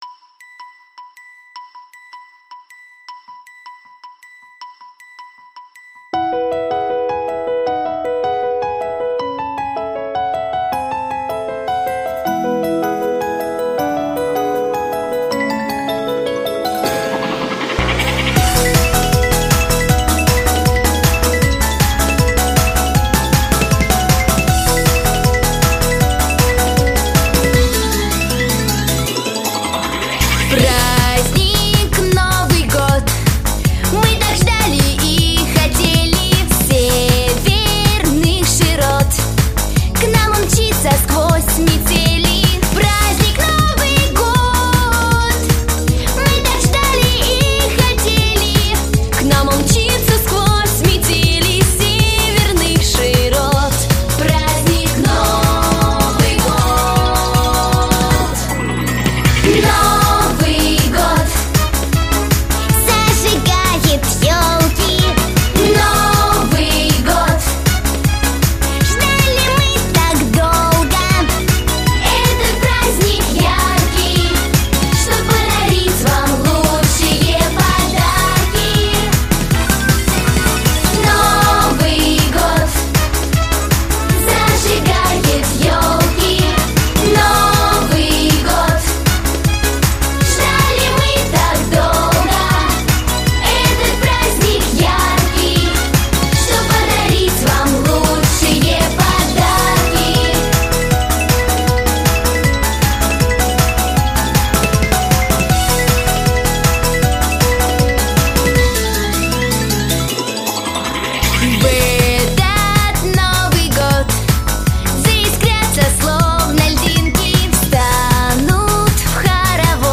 Новогодние песни